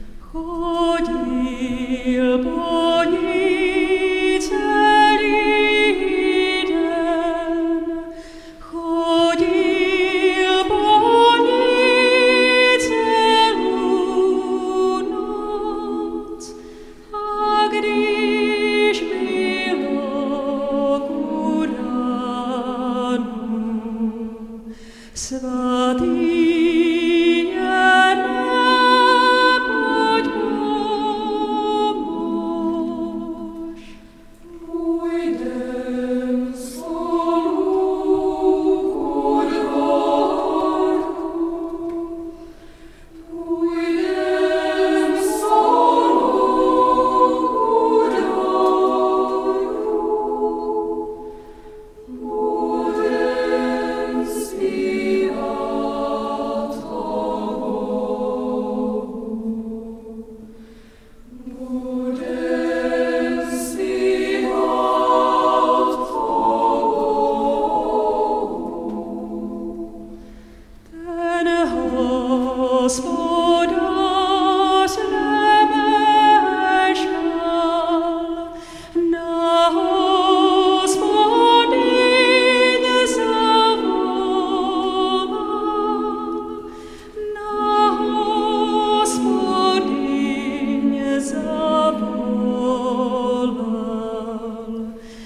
lidová - úprava Jiří Pavlica zpěv